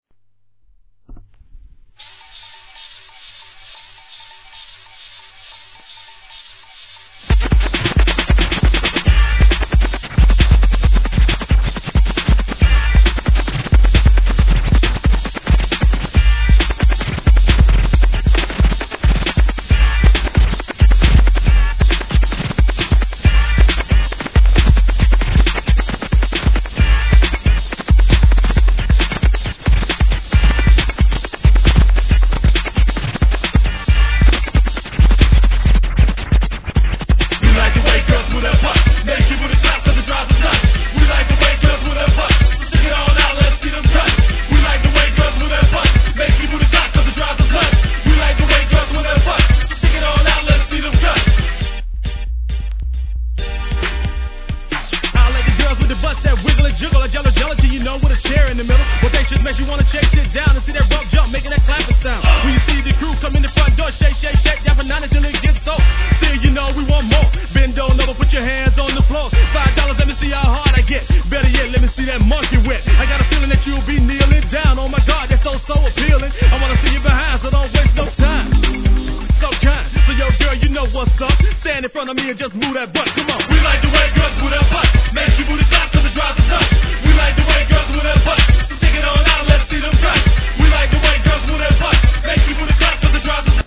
G-RAP/WEST COAST/SOUTH